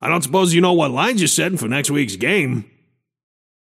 Shopkeeper voice line - I don’t suppose you know what lines you’re settin‘ for next weeks game?